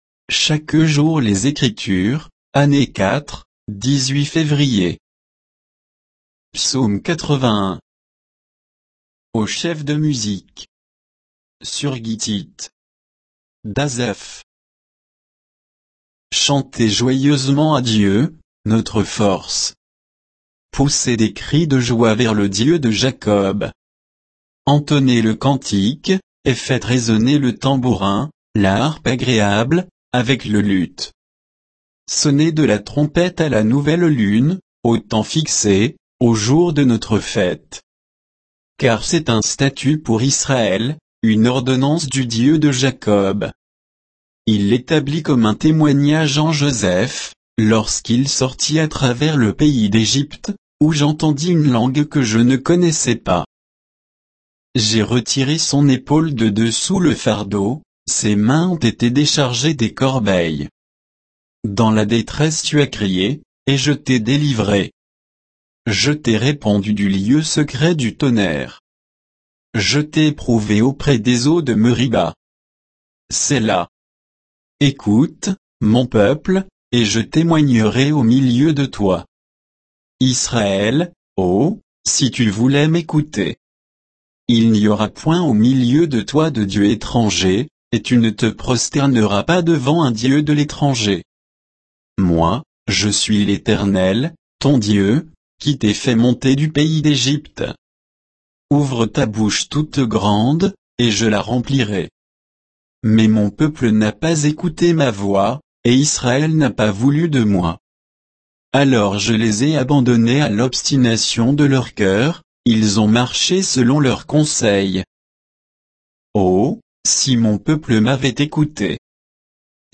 Méditation quoditienne de Chaque jour les Écritures sur Psaume 81